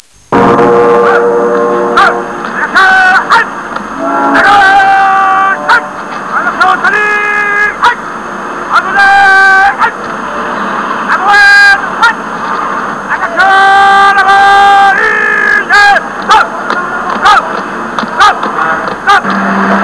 Vous trouverez sur cette page quelques sons tirés du film, reprenant, soit des parties du dialogue, soit tout simplement des phrases incontournables qui, je l' espére, vous remettront dans l'ambiance du film et vous rappelleront de bons souvenirs...